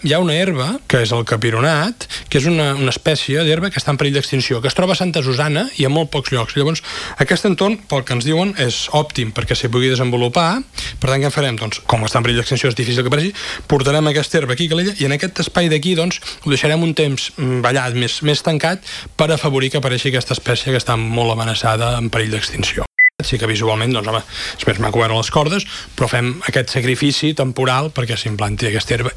En una entrevista A l’FM i +, l’alcalde Marc Buch ha explicat que es tracta d’una mesura temporal.